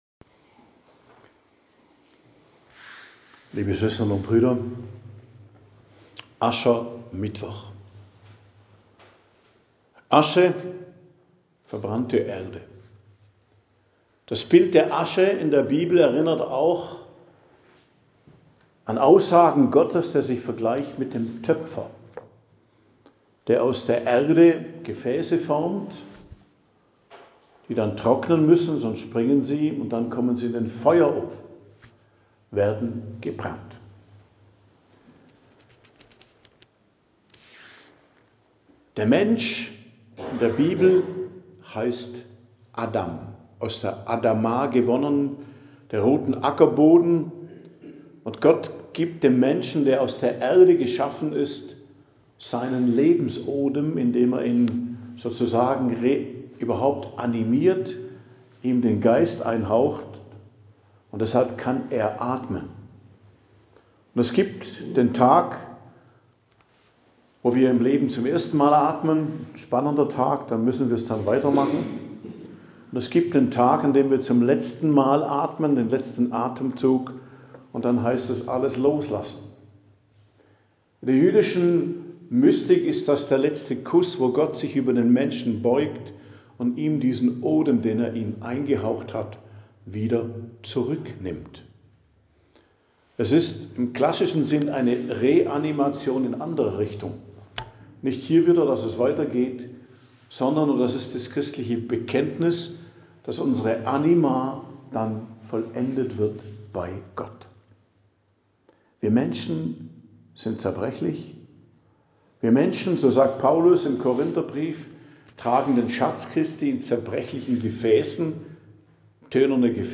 Predigt am Aschermittwoch, 18.02.2026